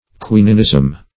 Quininism \Qui"nin*ism\